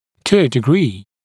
[tu ə dɪ’griː][ту э ди’гри:]до определенной степени, до какой-то степени